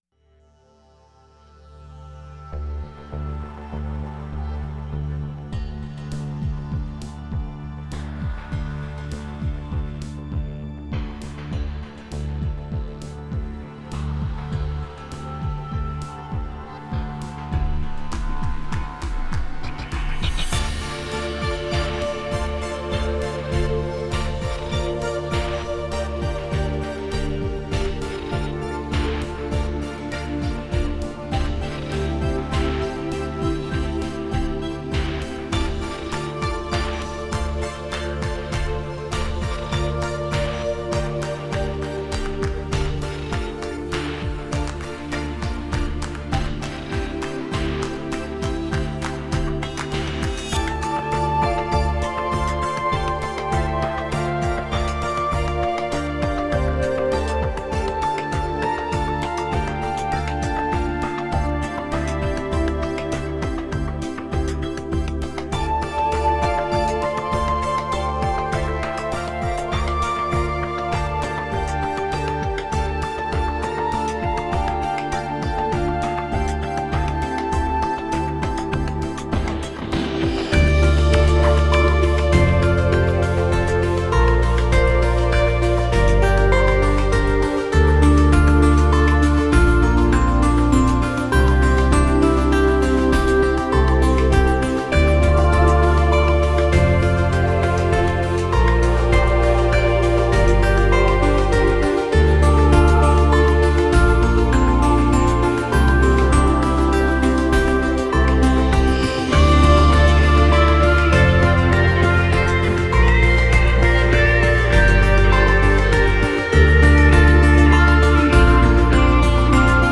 New age Медитативная музыка Нью эйдж